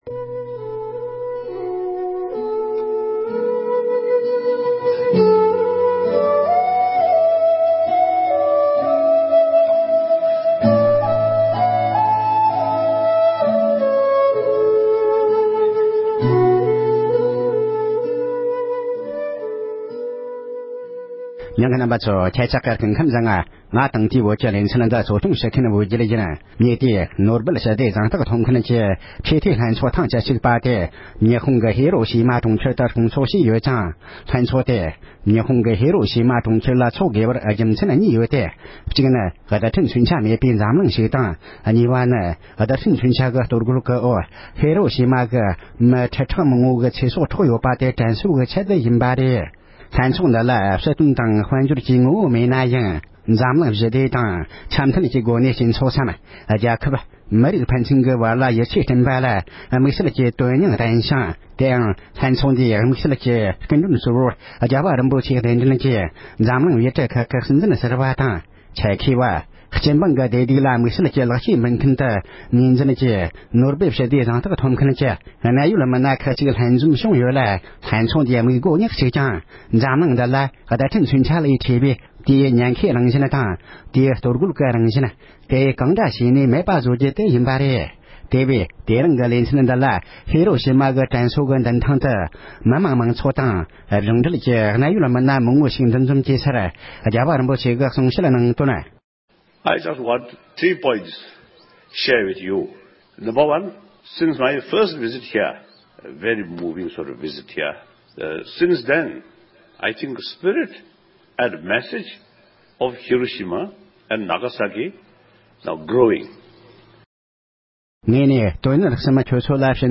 ༸གོང་ས་མཆོག་ནས་ཉི་ཧོང་དུ་བསྩལ་བའི་བཀའ་སློབ།
༸གོང་ས་མཆོག་གིས་ཉི་ཧོང་ཧེ་རོ་ཞི་མ་གྲོང་ཁྱེར་དྲན་རྟེན་མདུན་ཐང་དུ་བསྩལ་གནང་བའི་གལ་ཆེའི་བཀའ་སློབ་ཁག་ཕྱོགས་སྒྲིག་ཞུས་པ།